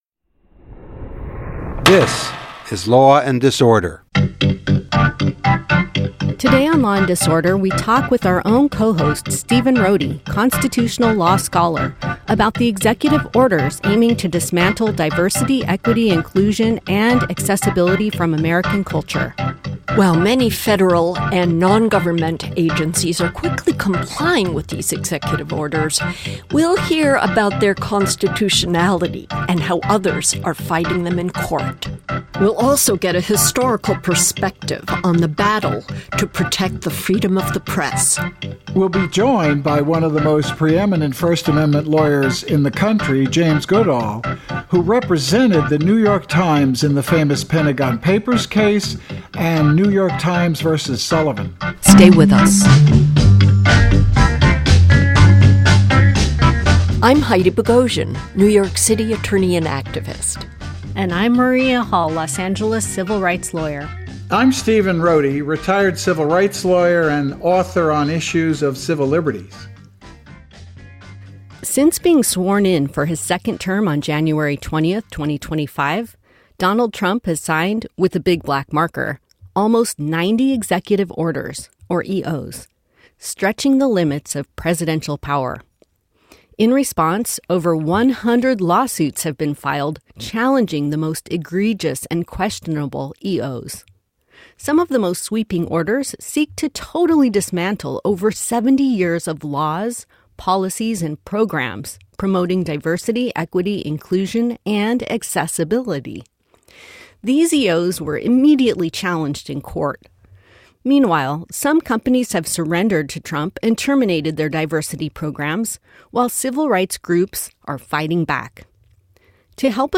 We speak with the economics professor Richard Wolff on why the economy was threatened with collapse and what must be done to protect us from the unstable banking system.